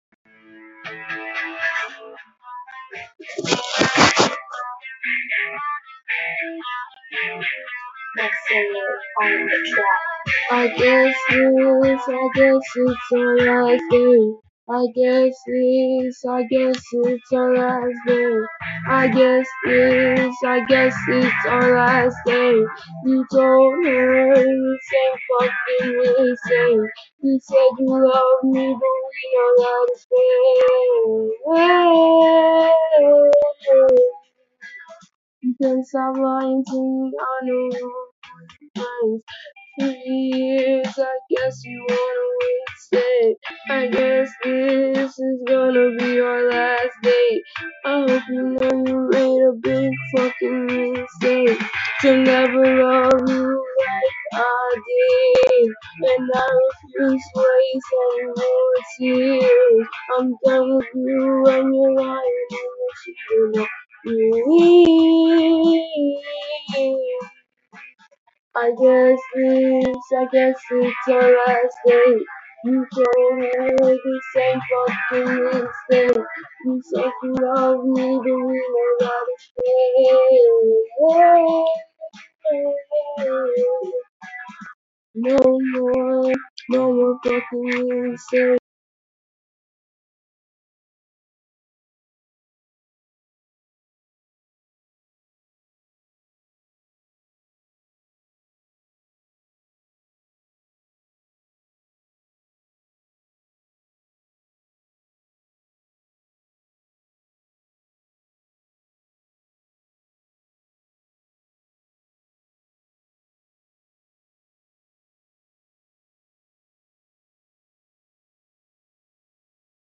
tried to record it, didnt turn out great but here
hadnt wrote and recorded a song in a long time